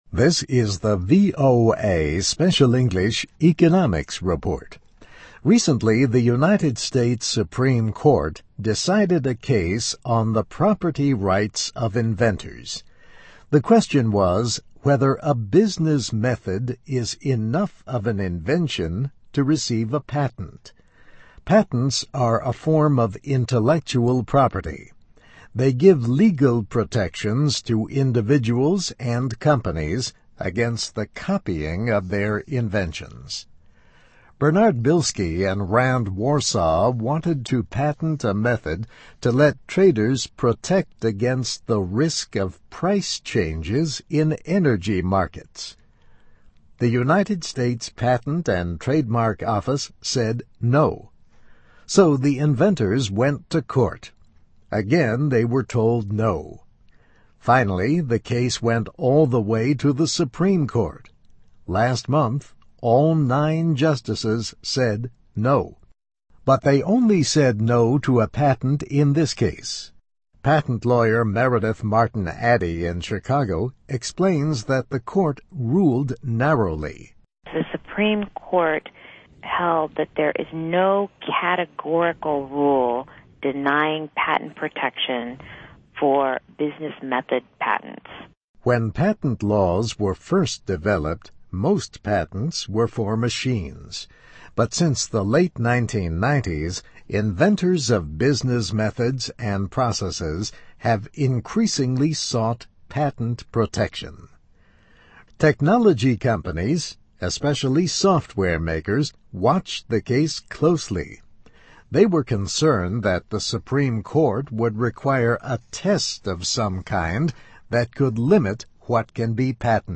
Inventors Given Hope on Patents for Business Methods (VOA Special English 2010-07-15)